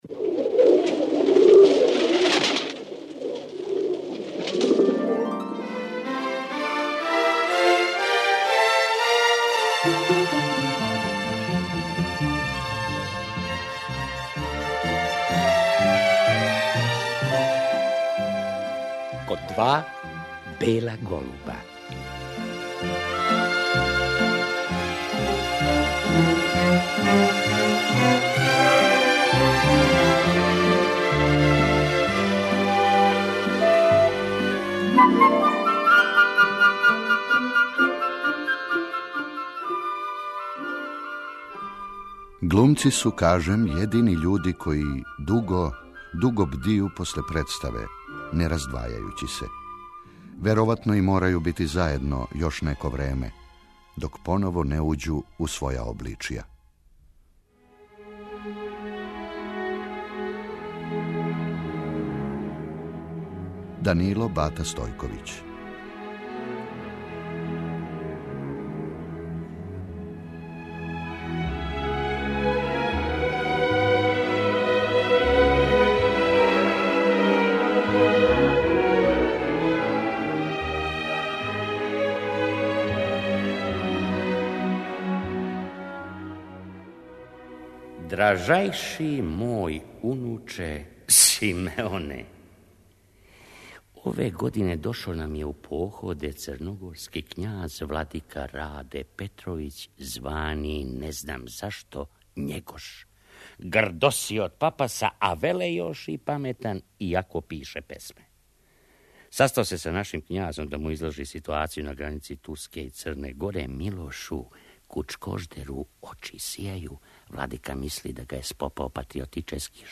Чућемо и снимак са доделе Добричиног прстена Бати Стојковићу 1990. године и одломке из радио адаптације "Корешподенције" Борислава Пекића.